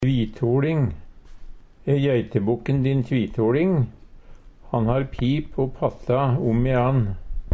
DIALEKTORD PÅ NORMERT NORSK tvitoLing tvekjønna Eksempel på bruk E jeitebokken din tvitoLing?